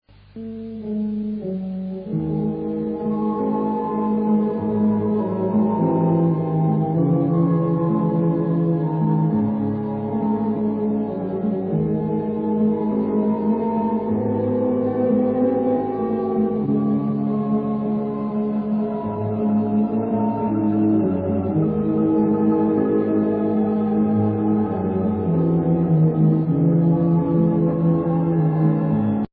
Ηχητική μπάντα παράστασης
ορχηστρικό